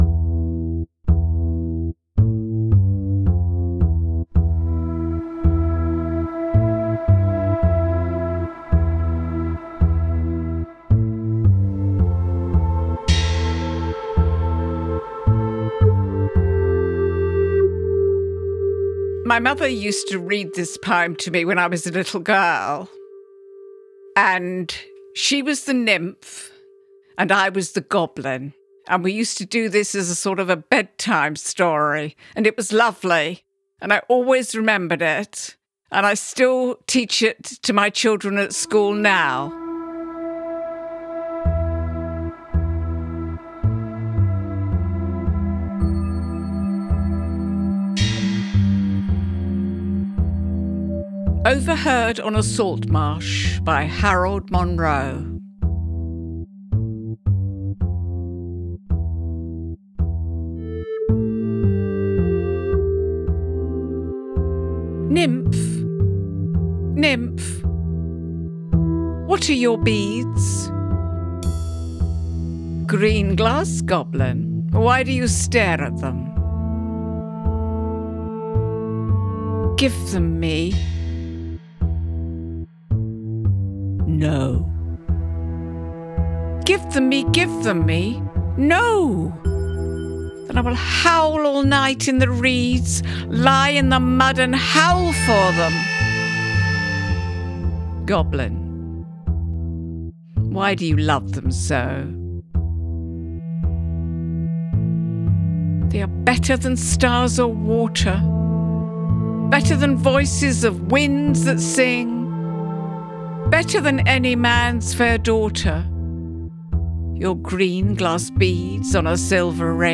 I have turned the recording into a sound design project, which you can listen to here.